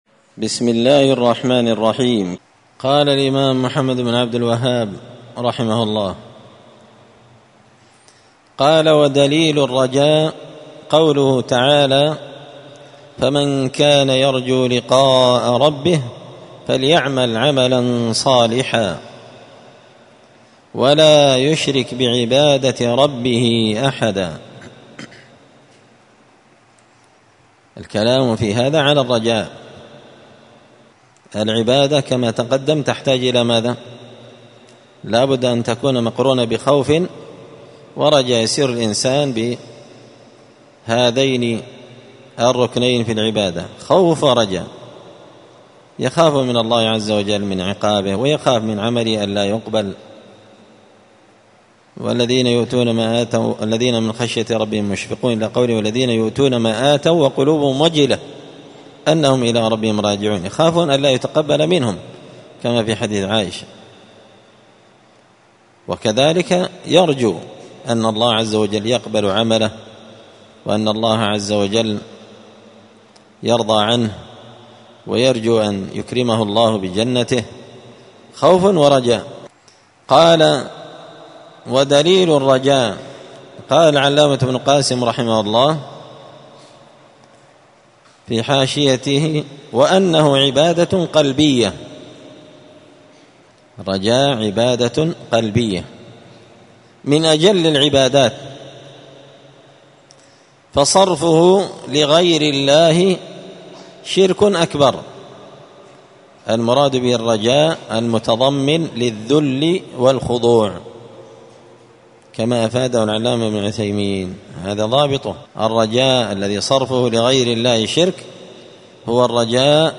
الأربعاء 24 ربيع الثاني 1445 هــــ | الدروس، حاشية الأصول الثلاثة لابن قاسم الحنبلي، دروس التوحيد و العقيدة | شارك بتعليقك | 102 المشاهدات
مسجد الفرقان قشن_المهرة_اليمن